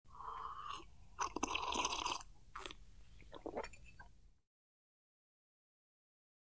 Sip And Slurp Liquid With Swallow And Light Glass Clink